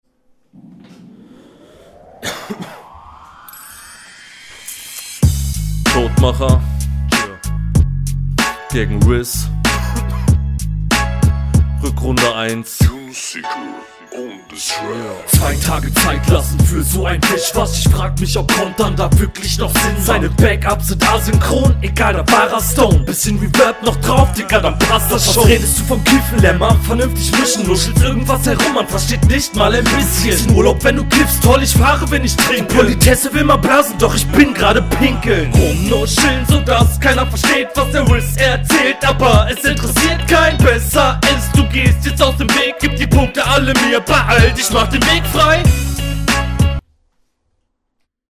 Dein Einstieg und deine Stimme find ich fresh!
Die Mische hier ist definitiv besser und ich verstehe alles problemlos.